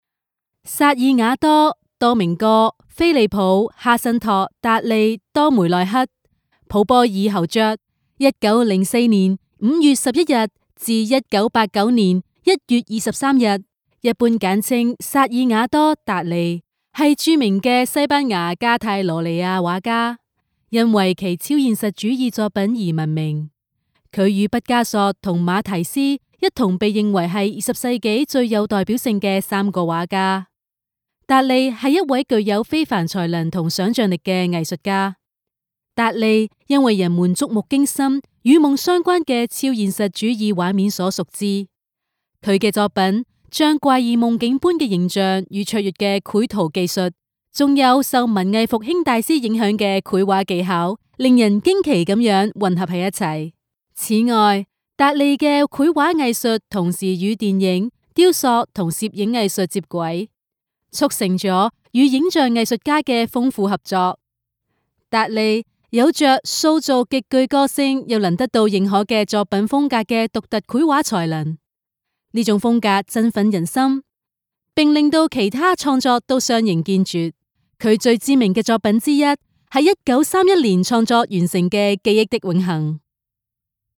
Female
Natural, engaging, authentic, with strong professional delivery
Tour Guide In Museum